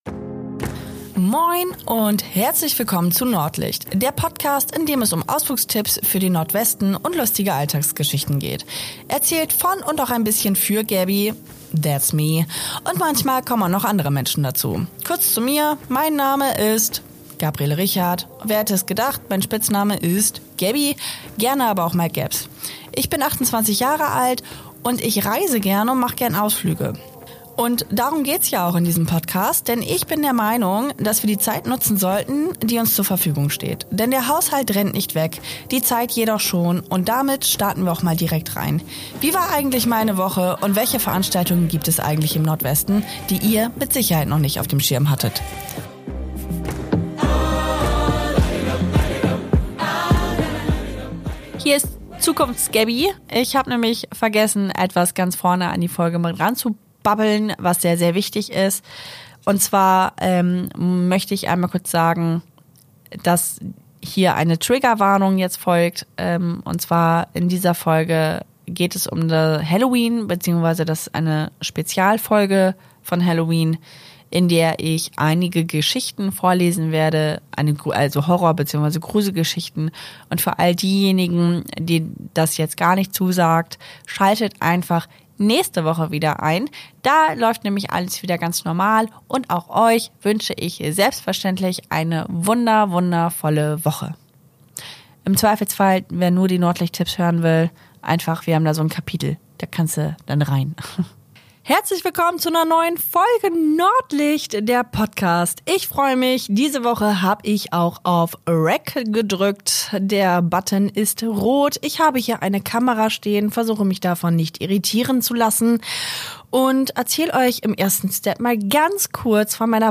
TRIGGERWARNUNG: Dies ist eine Special- Halloween - Folge in der ich unterschiedliche Gruselgeschichten vorlese.